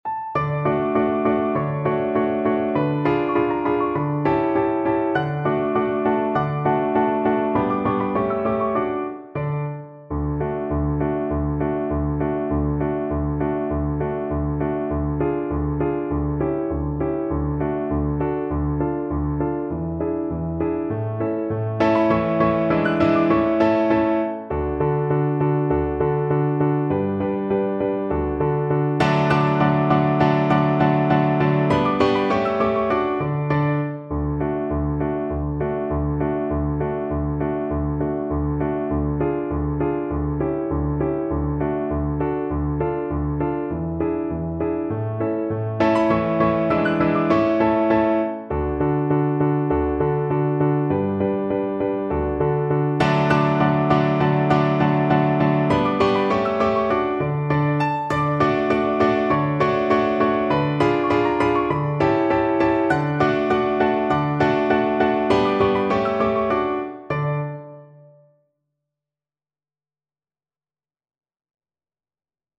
Cello version
2/4 (View more 2/4 Music)
Allegro non troppo (View more music marked Allegro)
Classical (View more Classical Cello Music)